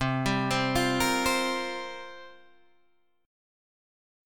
C7sus4 chord